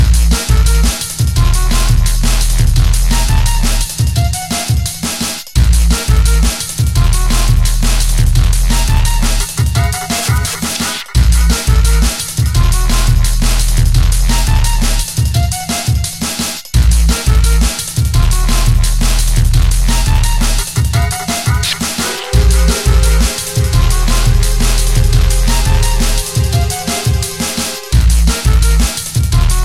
TOP > Jump Up / Drum Step